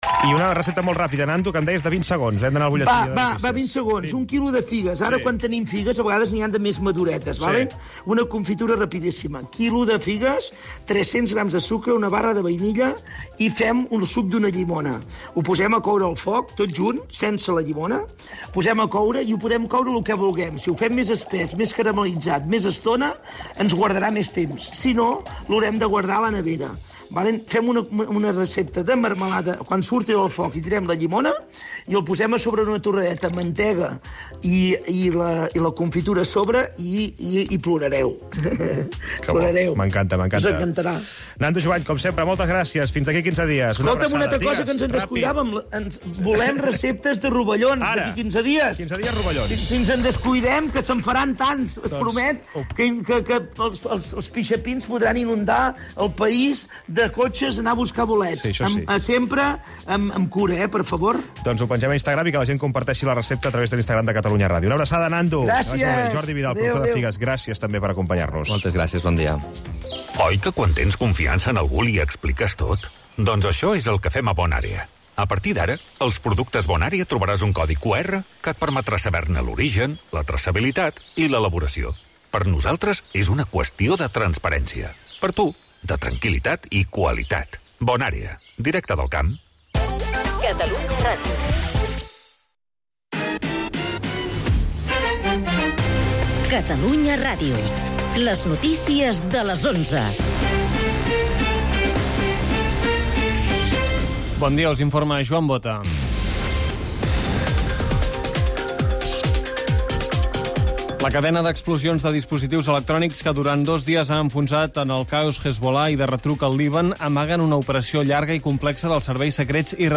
El mat, d'11 a 12 h (entrevista i humor) - 19/09/2024
Els actors Javier Cmara i Mnica Lpez presenten al Mat de Catalunya Rdio la tercera temporada de la srie "Rapa".